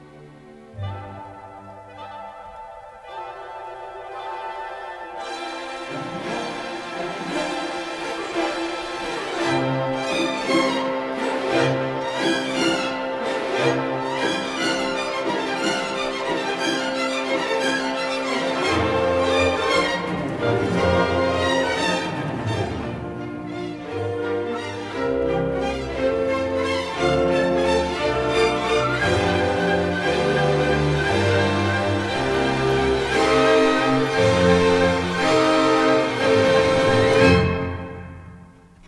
Stereo recording made in February 1960